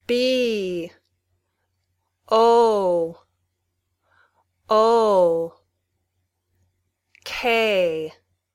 Click "Listen" to hear a word spelled out.